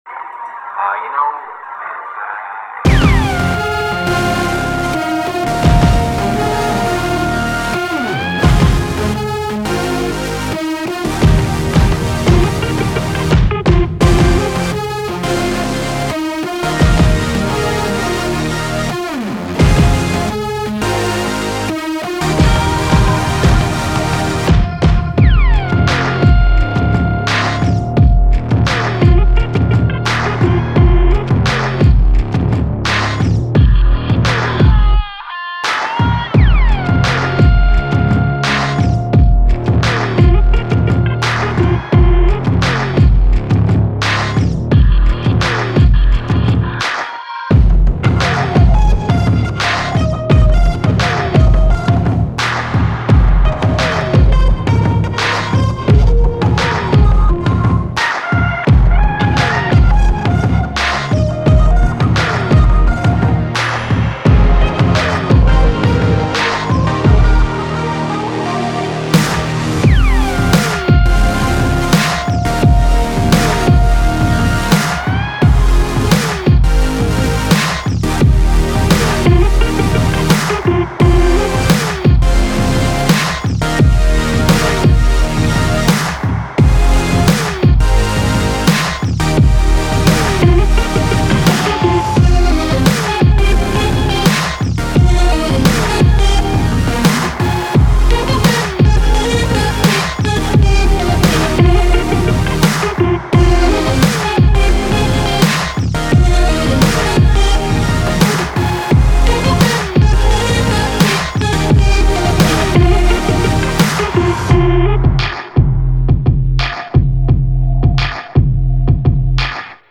Категория: Electro